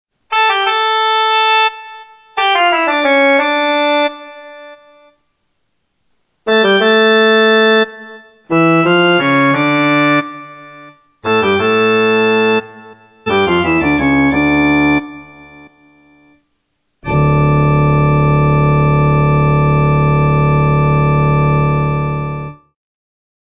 Rameau's. The theme contains all notes characteristic notes of the key of D minor.
Moreover, the first three notes stress the A, making it heard repeatedly and for some time.